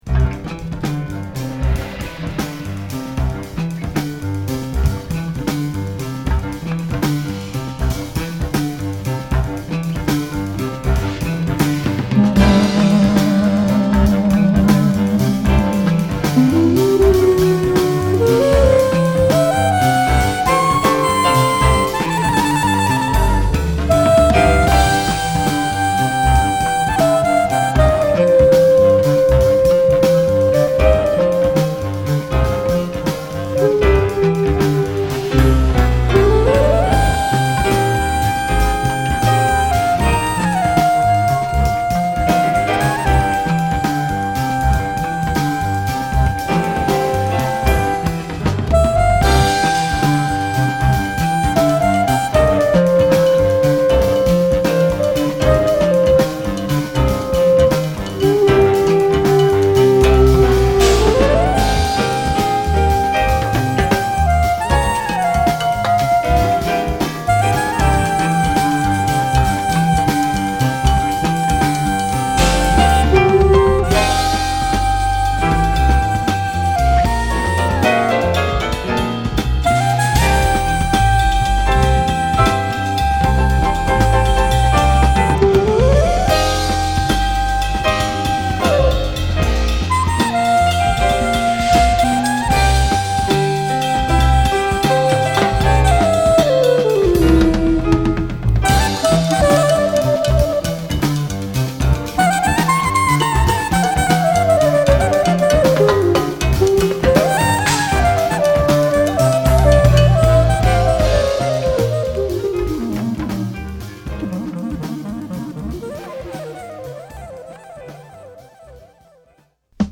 クラリネットの響きがひんやりとした空気感を漂うわせるA1